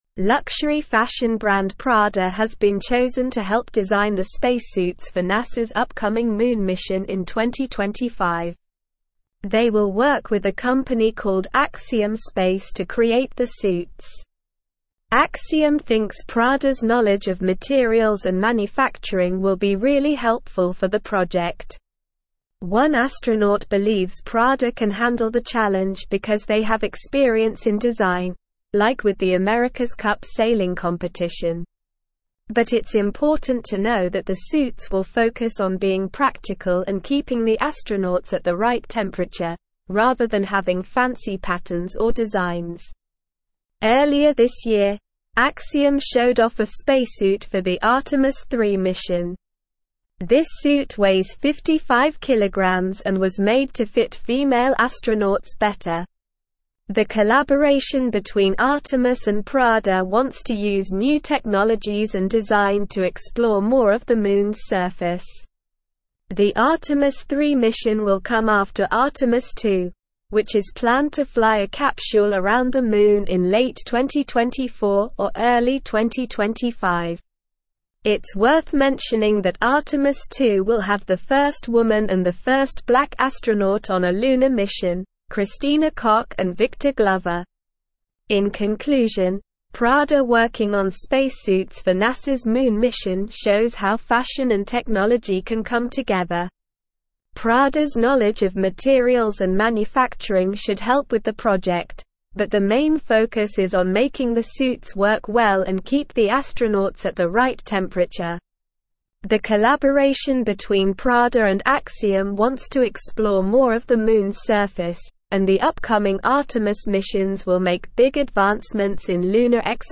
Slow
English-Newsroom-Upper-Intermediate-SLOW-Reading-Prada-and-Axiom-Space-Collaborate-on-NASA-Moon-Mission.mp3